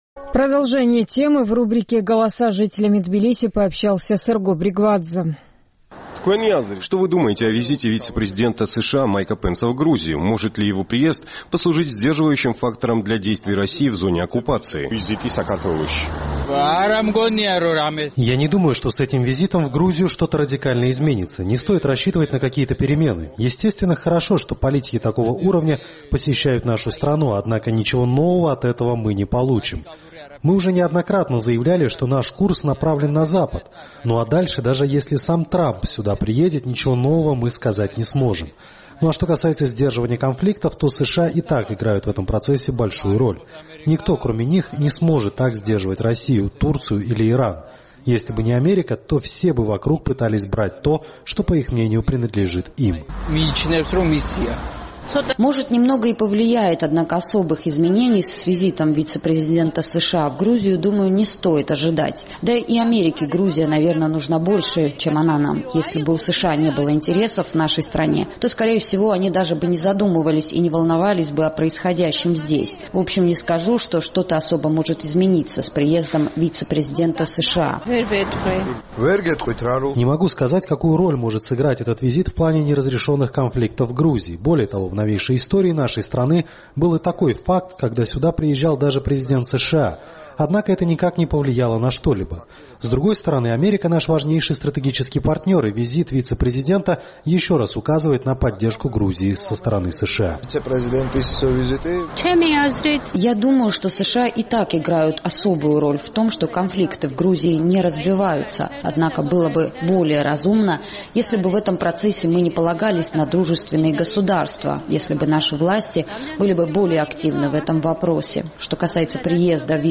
Наш тбилисский корреспондент поинтересовался у местных жителей, что они думают о визите вице-президента США Майка Пенса в Грузию, и может ли его приезд послужить сдерживающим фактором для действий России в зоне оккупации